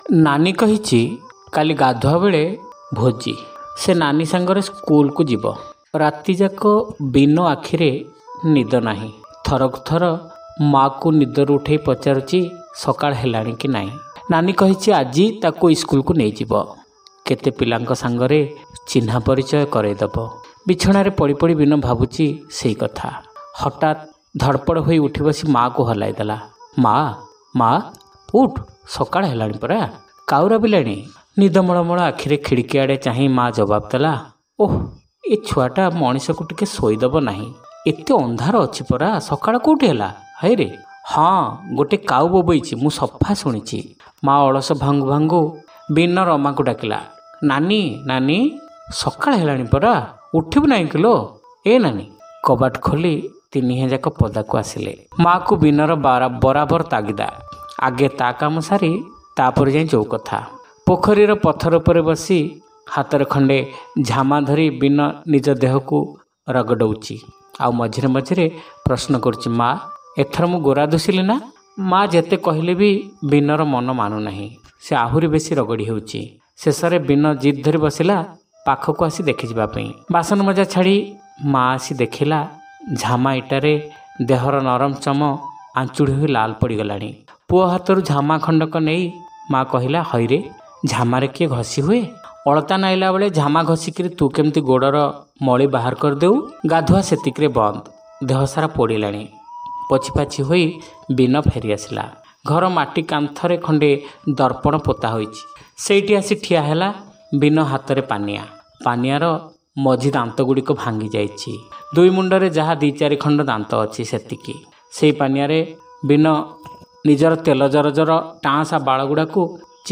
ଶ୍ରାବ୍ୟ ଗଳ୍ପ : ଭୋଜି (ତୃତୀୟ ପର୍ଯ୍ୟାୟ)